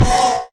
骷髅马：受伤
骷髅马在受伤时随机播放这些音效
Minecraft_skeleton_horse_hurt1.mp3